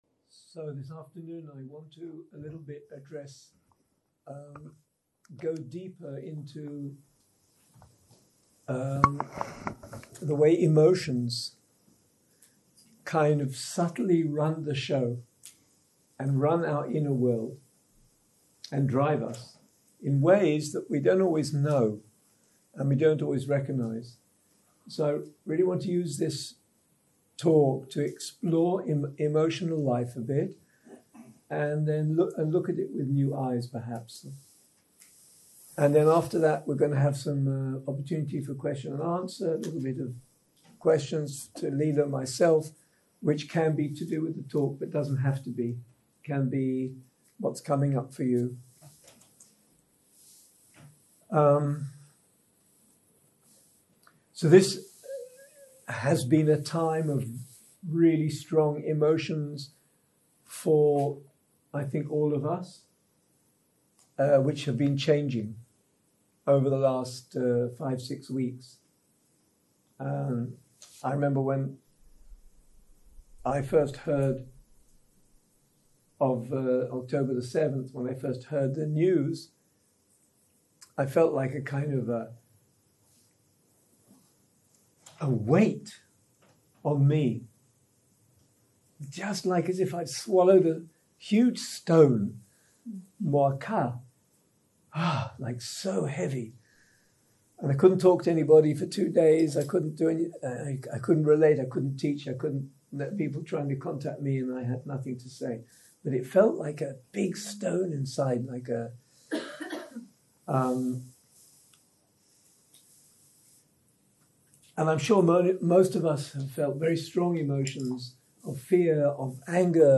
סוג ההקלטה: שיחות דהרמה